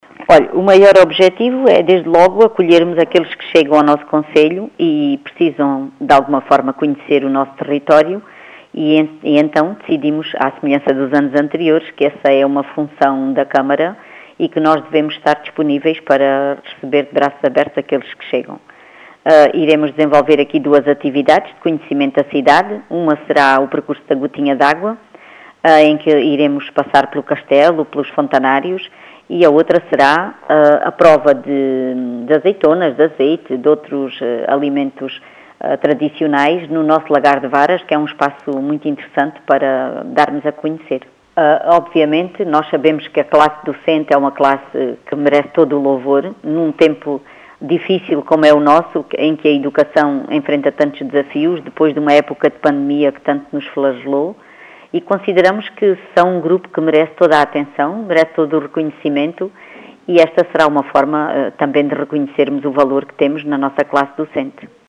As explicações são de Lurdes Balola, vereadora da Câmara Municipal de Moura.